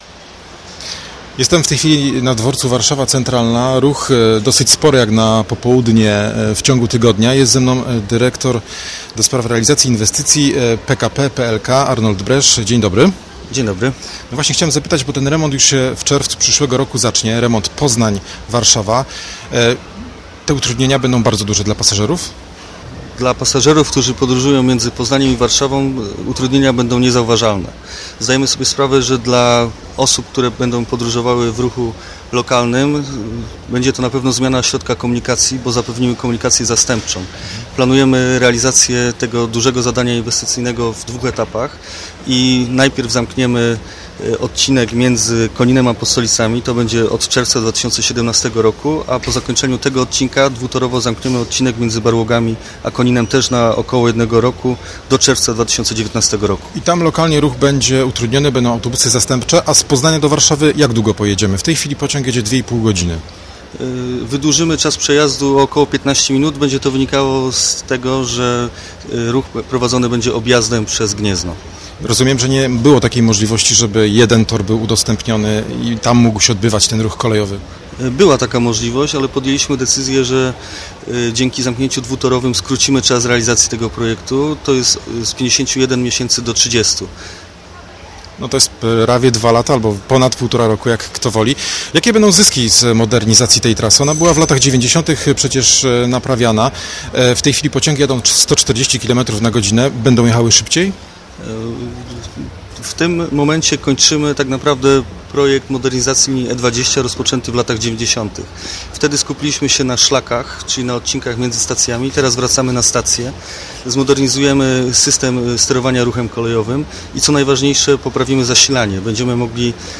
Nasz reporter ustalał to w Warszawie.